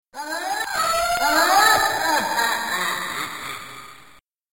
splatter_jentransform.mp3